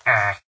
minecraft / sounds / mob / villager / no2.ogg